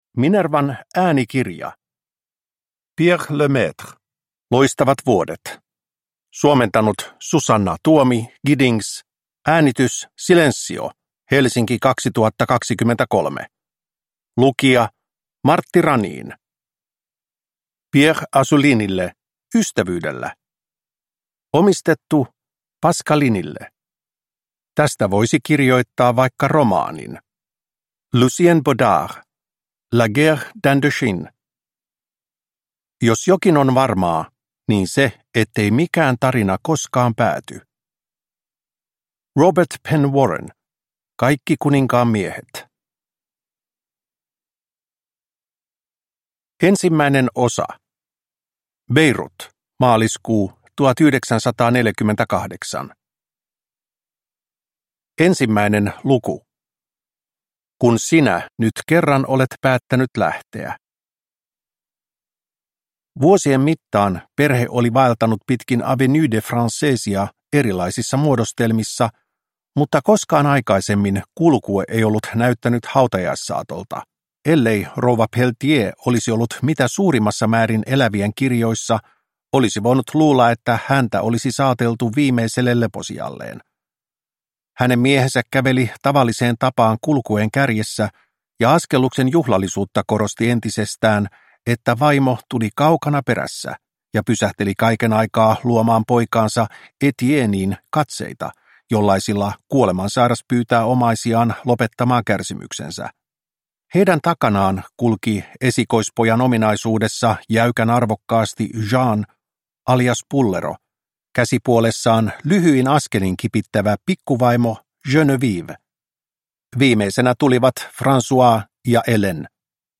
Loistavat vuodet – Ljudbok – Laddas ner